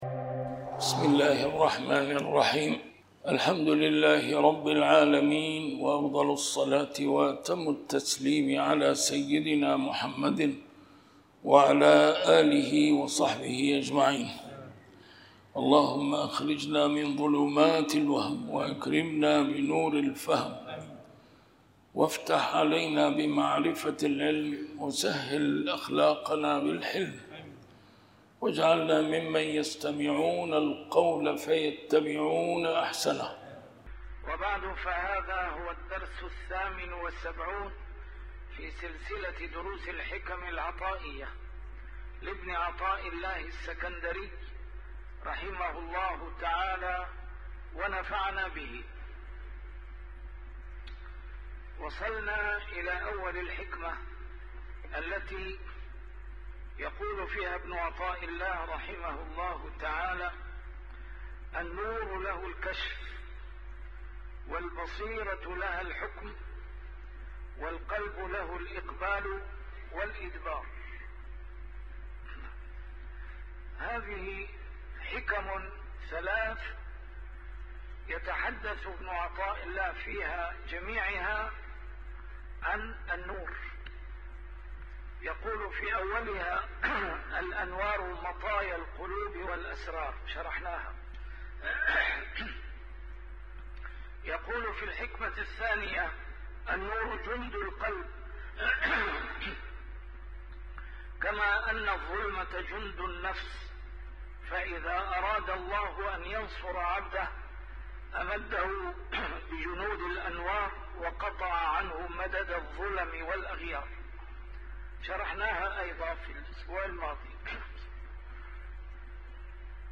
A MARTYR SCHOLAR: IMAM MUHAMMAD SAEED RAMADAN AL-BOUTI - الدروس العلمية - شرح الحكم العطائية - الدرس رقم 78 شرح الحكمة 57